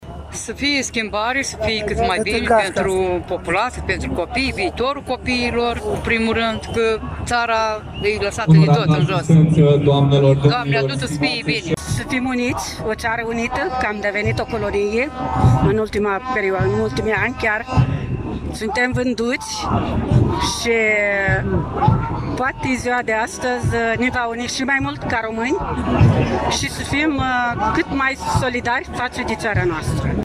Peste 5.000 de persoane se află în Piaţa Unirii din Iaşi, pentru a celebra 166 de ani de la înfăptuirea Unirii Principatelor Române.
Astăzi, în zi de sărbătoare, românii, au așteptări mari de la cei care ne conduc:
24-ian-rdj-12-Vox-Ziua-Unirii.mp3